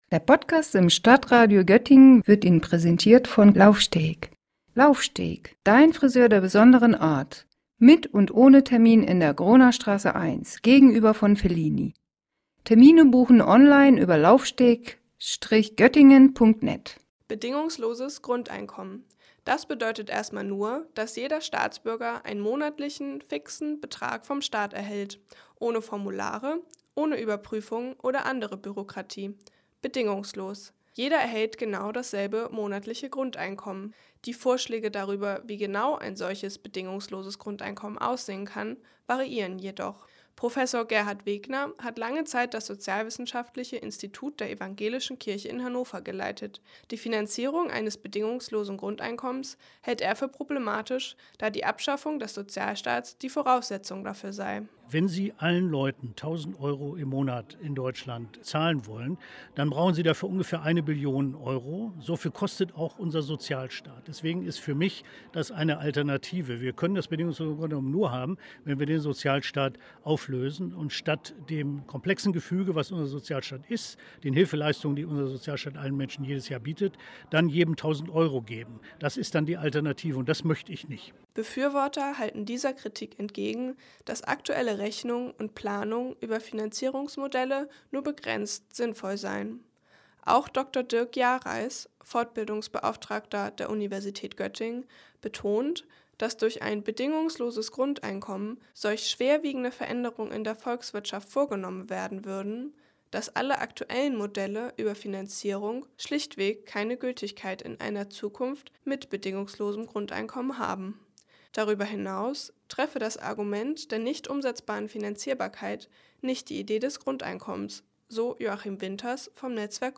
Fest steht, dass ein bedingungsloses Grundeinkommen einiges an Veränderung mit sich bringen würde. Um so kontroverser wird die Debatte um das Grundeinkommen geführt und das auch hier in Göttingen: Am vergangenen Freitag hatten die evangelische Stadtakademie und die Göttinger Regionalgruppe der Gemeinwohl-Ökonomie zu einer Podiumsdiskussion eingeladen.